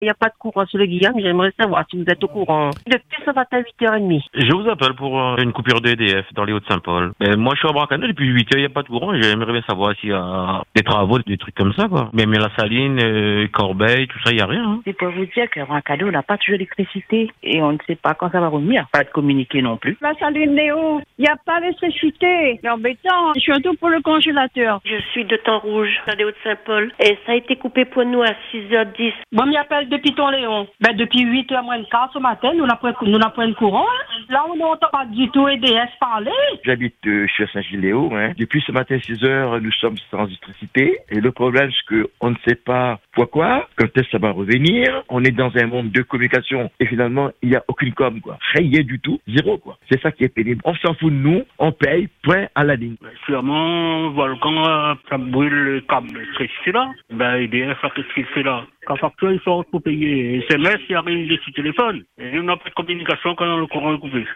Sur l’antenne, vous avez été nombreux à réagir, dénonçant surtout le manque de communication autour de cet incident.